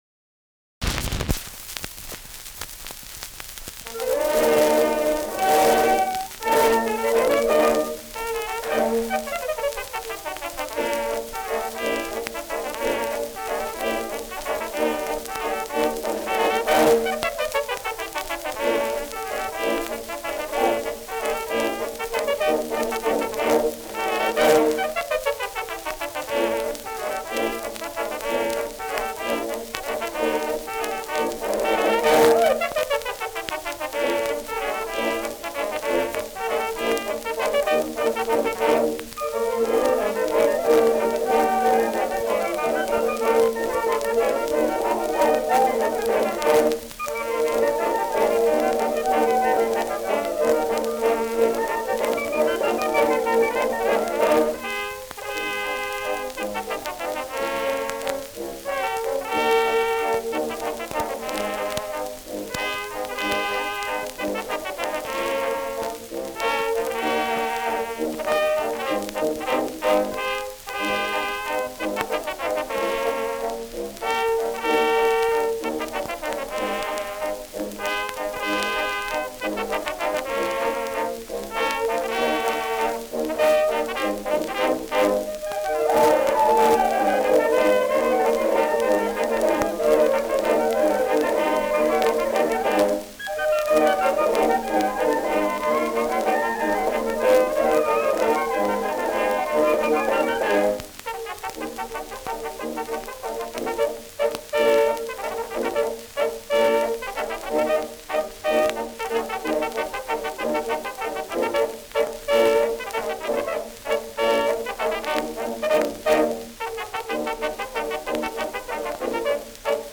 Schellackplatte
Stärkeres Grundrauschen : Gelegentlich leichtes bis stärkeres Knacken : Hängt am Schluss